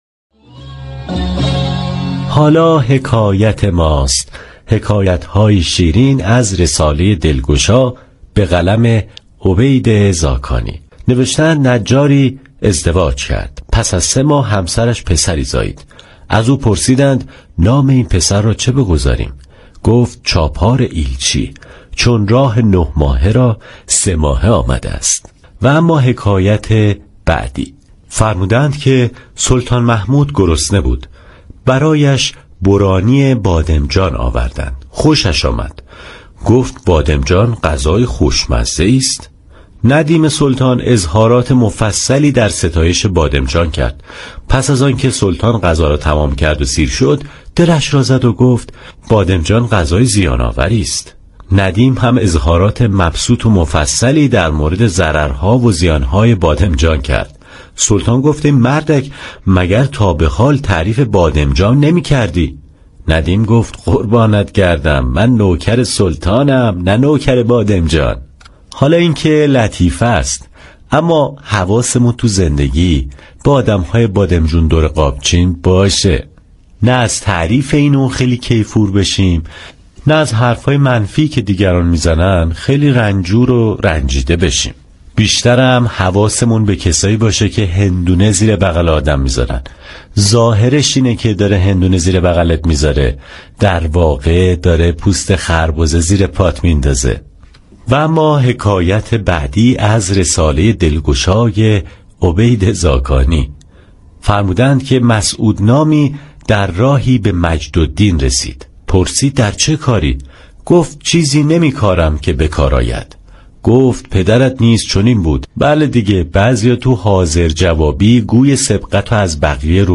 حكایت طنز